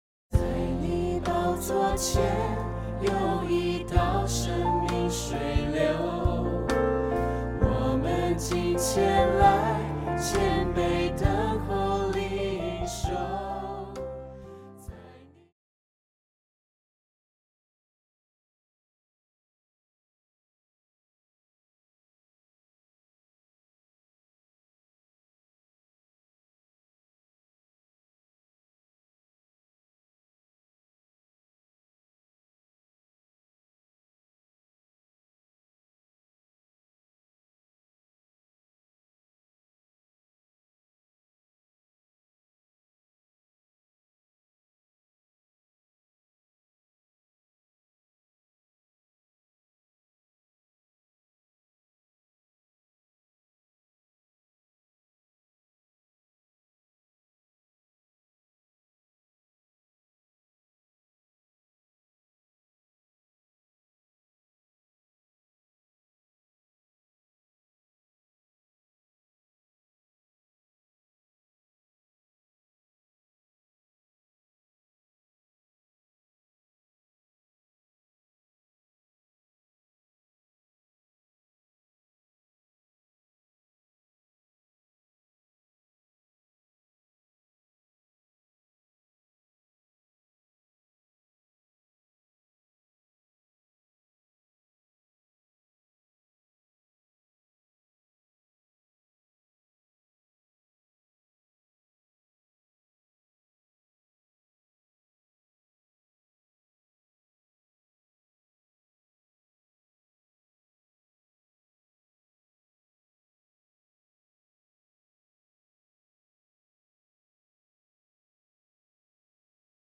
电贝斯
乐团
教会音乐
演奏曲
独奏与伴奏
有主奏
有节拍器